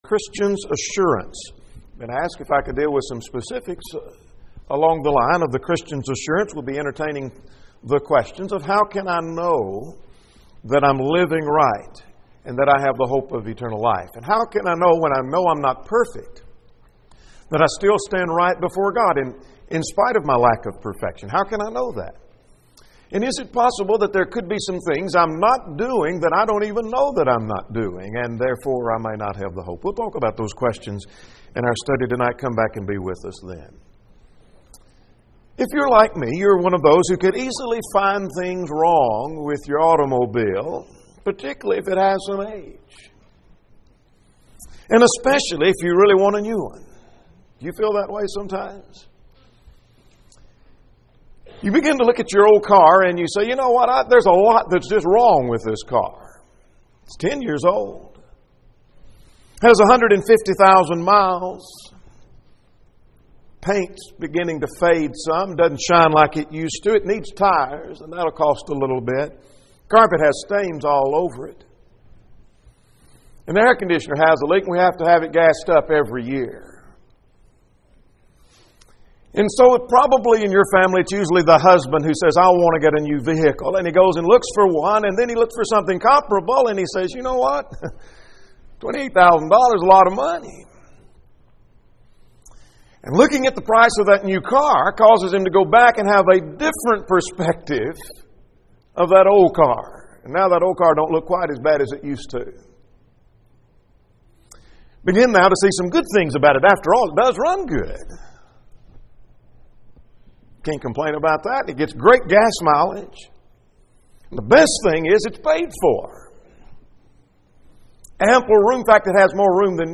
Sermons in Mp3 & PowerPoint